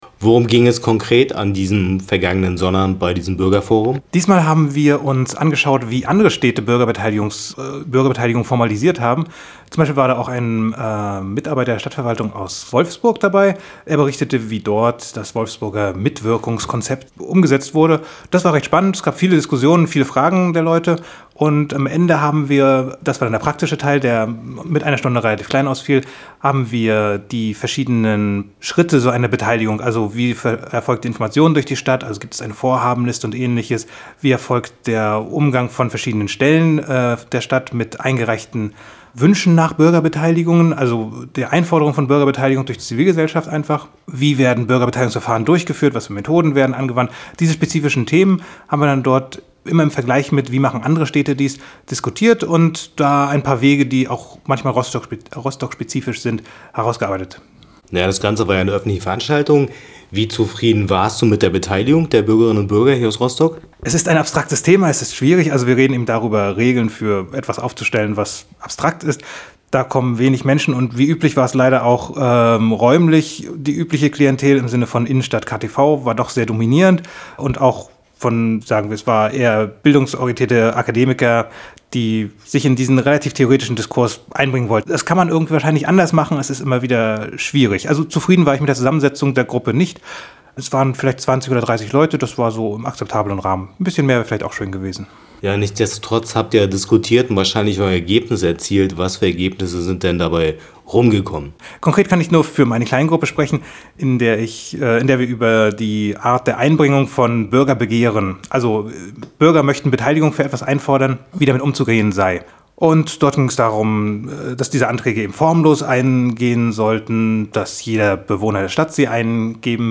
Mehr hier im Interview: